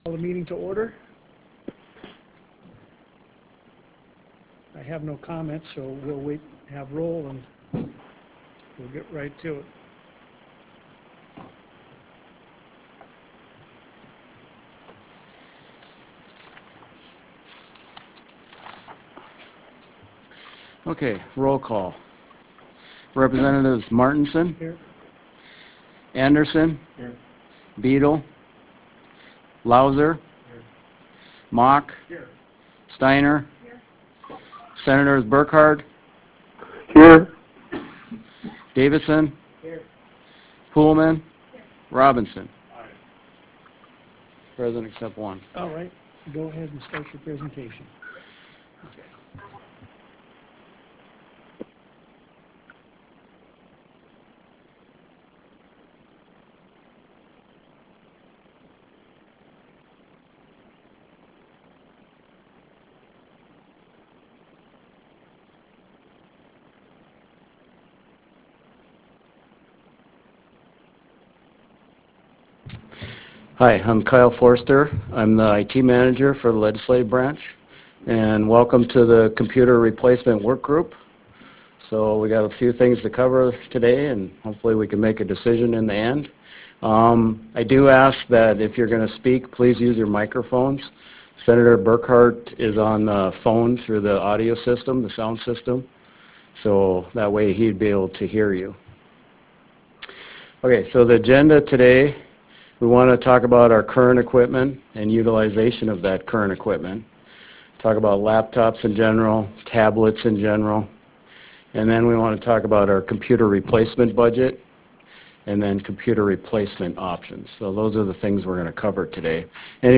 Harvest Room State Capitol Bismarck, ND United States